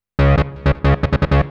hous-tec / 160bpm / bass